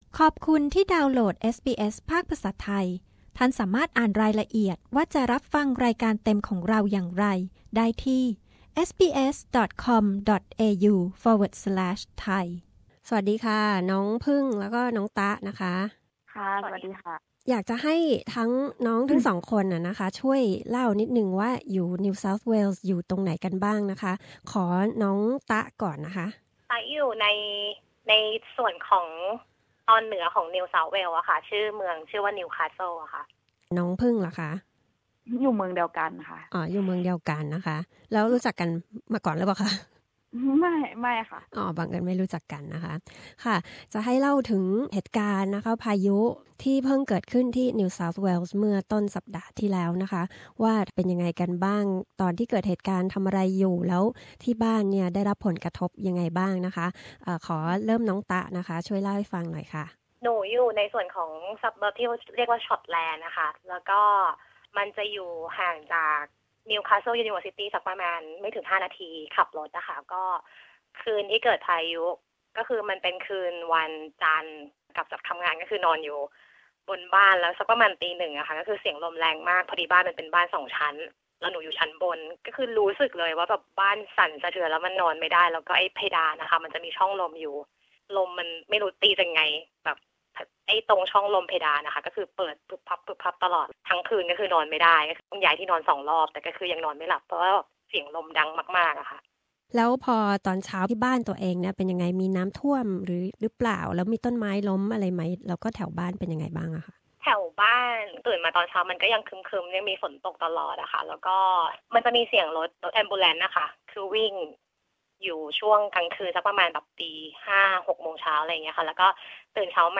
Two Thai residents of New Castle shared their experiences about the recent devastating storm and flooding in NSW. What they encountered, how they coped and what lessons they learned from it.